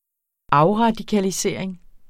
Udtale [ ˈɑwʁɑdikaliˌseˀɐ̯eŋ ]